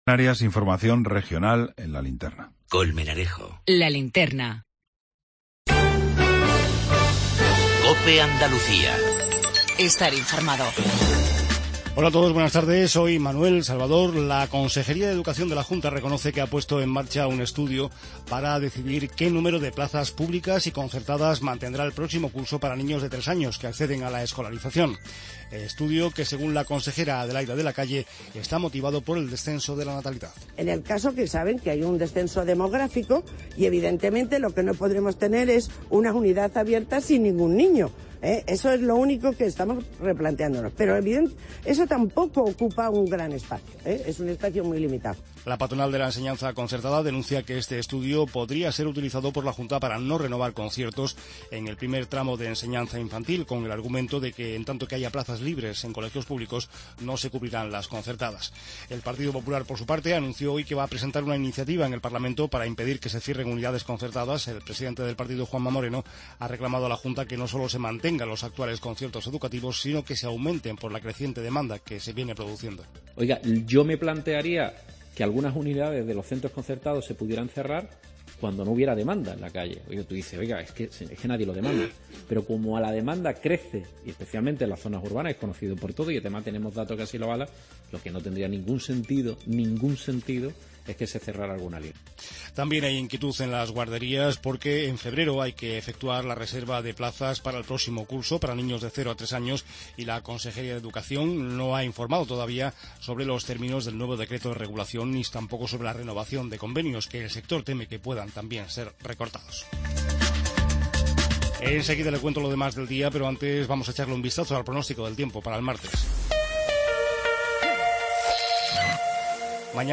INFORMATIVO REGIONAL TARDE